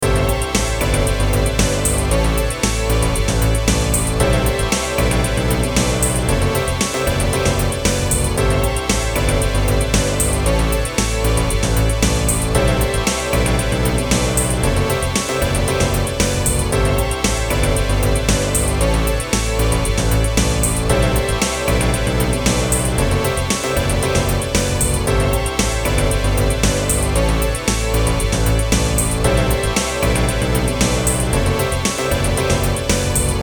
テンポ110のゆったりとしたダンス着信音。